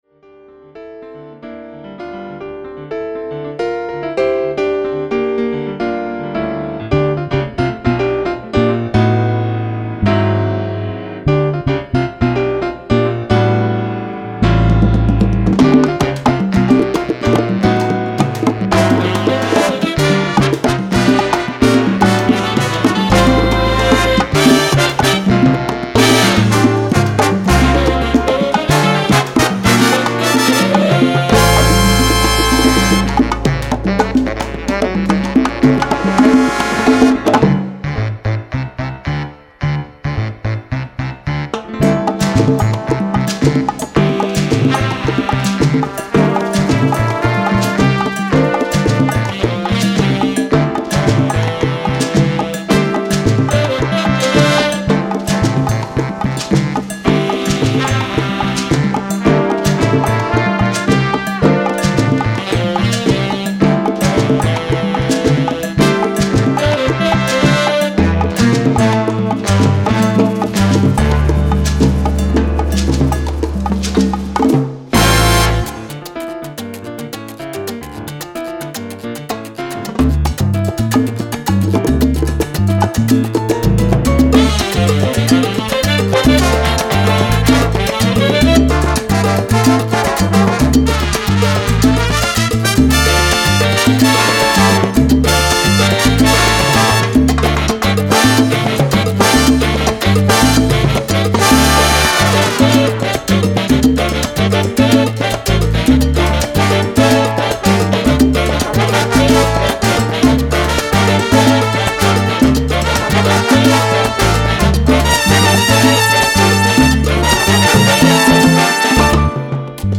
Category: big band
Style: mambo
Solos: piano, percussion
Instrumentation: big band (4-4-5, rhythm (4)